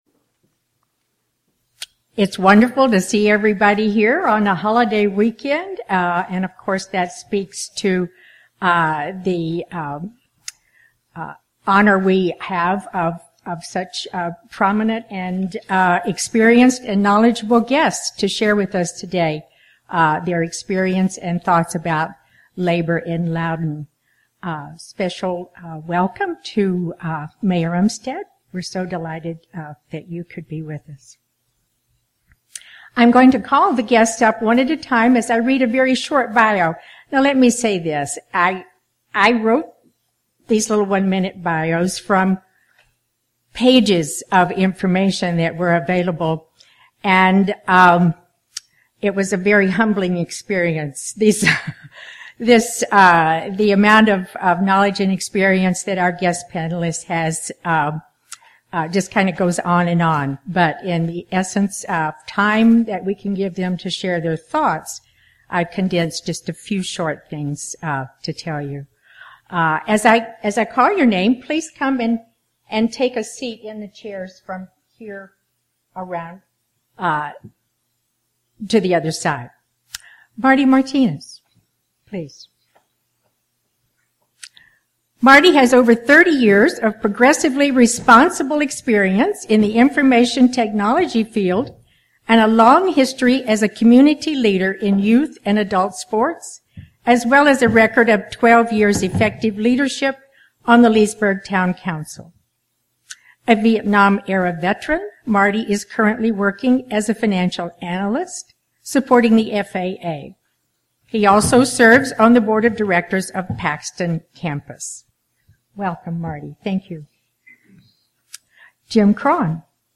This sermon documents a community panel discussion regarding the evolving landscape of labor and economic development in Loudoun County and Leesburg, Virginia.